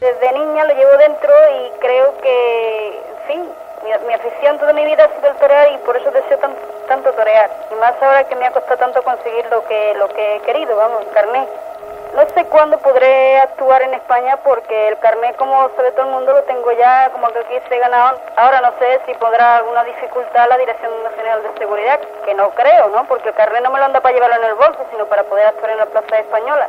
Declaracions telefòniques
Informatiu